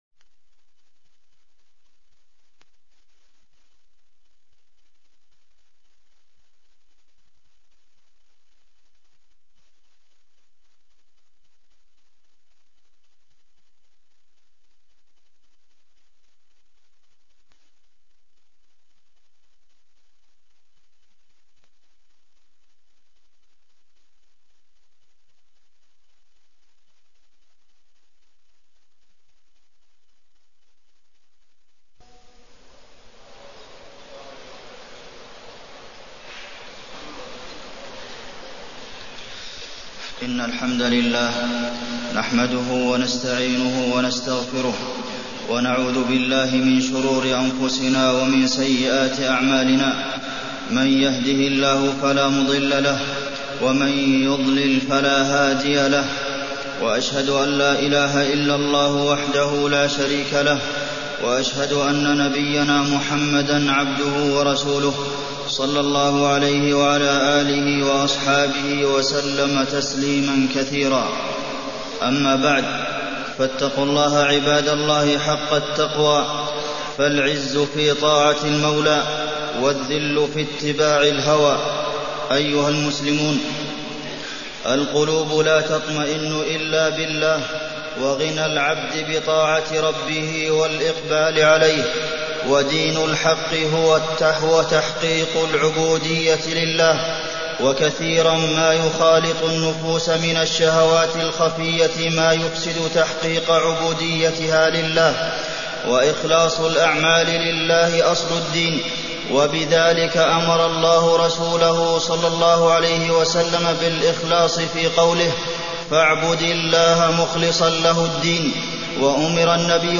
تاريخ النشر ٢٤ جمادى الآخرة ١٤٢٤ هـ المكان: المسجد النبوي الشيخ: فضيلة الشيخ د. عبدالمحسن بن محمد القاسم فضيلة الشيخ د. عبدالمحسن بن محمد القاسم الإخلاص